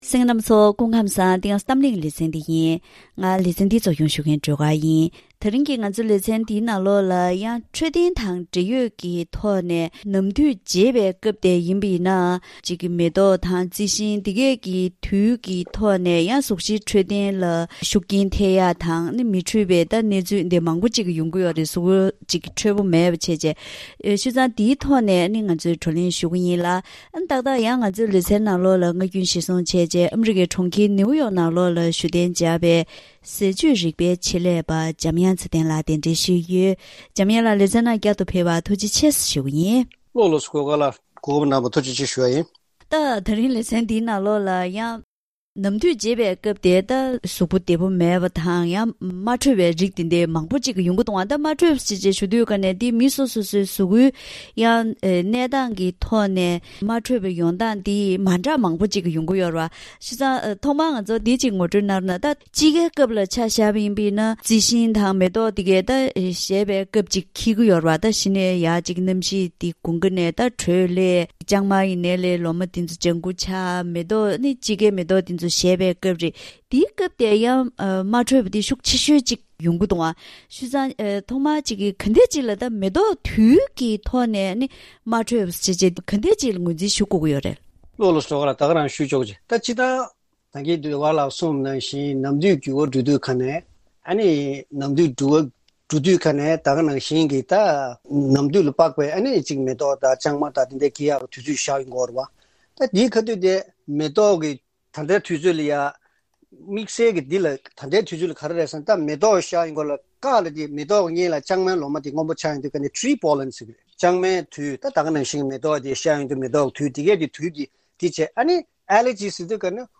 ད་རིང་གི་གཏམ་གླེང་ཞལ་པར་ལེ་ཚན་ནང་ནམ་དུས་བརྗེ་པའི་སྐབས་རྩི་ཤིང་དང་མེ་ཏོག་རྡུལ་གྱི་མ་འཕྲོད་པ་དང་། དེ་བཞིན་ཟས་རིགས་ཀྱི་མ་འཕྲོད་པའི་དབང་གིས་གཟུགས་གཞི་མི་བདེ་བ་ཡོང་གི་ཡོད་པས། ཉིན་རེའི་འཚོ་བའི་ནང་ཟས་རིགས་དང་འཚོ་བའི་གོམས་གཤིས་ལ་བསྒྱུར་བ་བཏང་པའི་ཐོག་ནས་སྔོན་འགོག་ཡོང་ཐོབས་དང་། ཟས་བཅུད་ལ་དོ་སྣང་ཇི་ལྟར་དགོས་ཚུལ་སོགས་ཀྱི་ཐོག་ཟས་བཅུད་རིག་པའི་ཆེད་ལས་པ་དང་ལྷན་དུ་བཀའ་མོལ་ཞུས་པ་ཞིག་གསན་རོགས་གནང་།